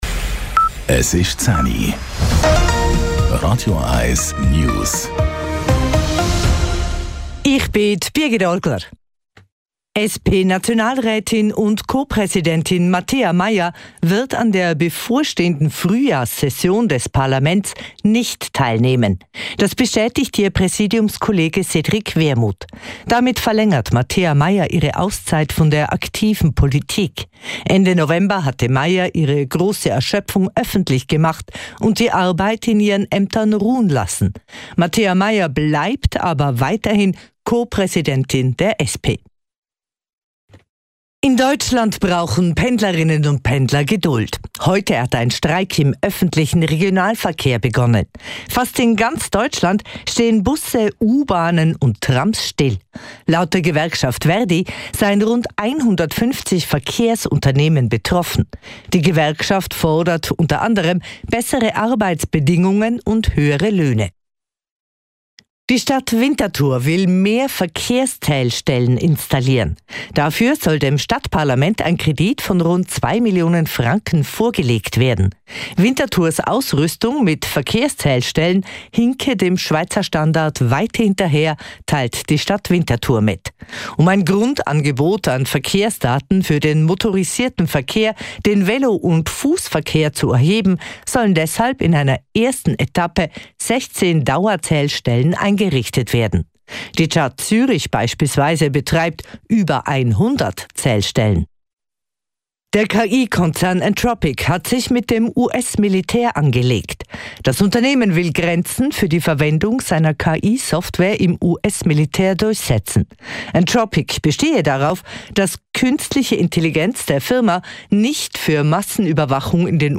Nachrichten & Politik